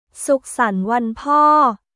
スックサン・ワン・ポー